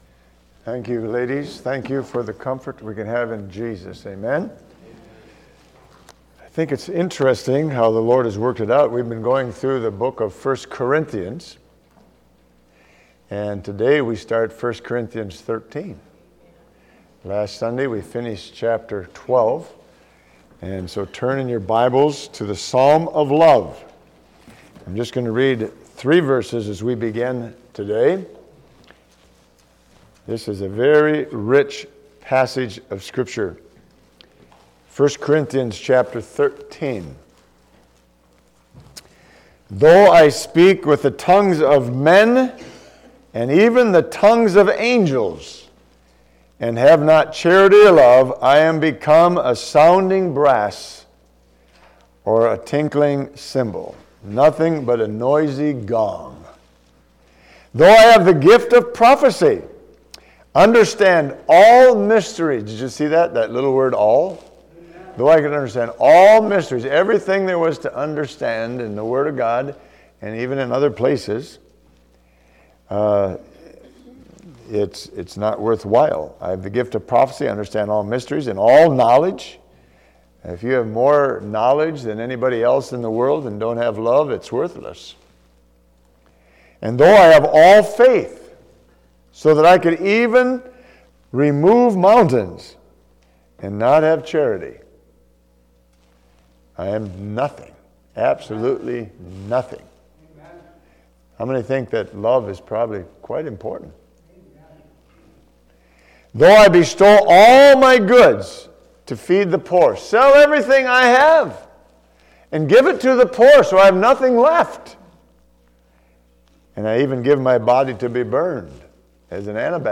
Genre: Sermons.